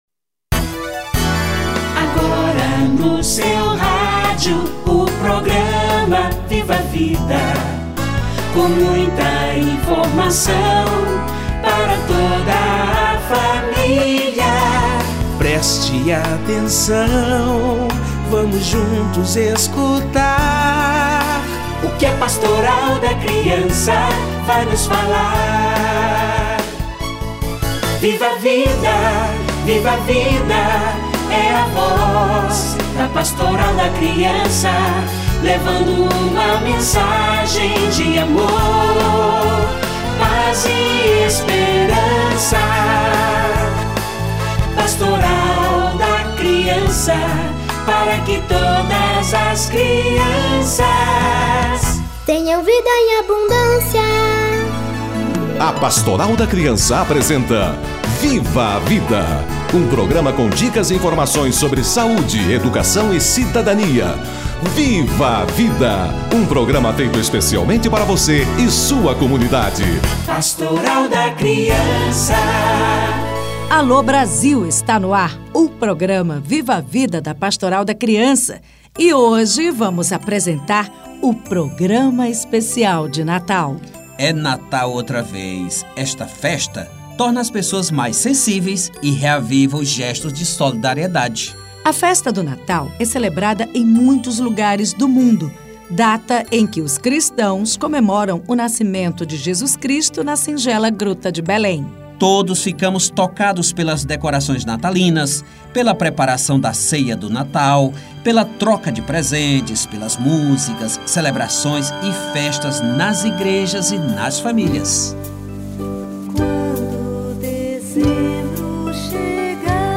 Natal - Entrevista